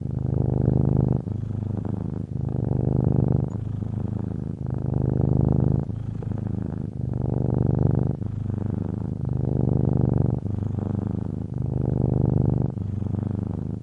胖胖的呼噜声贾巴猫小猫呼噜声
描述：我的小胖猫在打呼噜
标签： 小猫 小猫 PUR 咕噜咕噜叫
声道立体声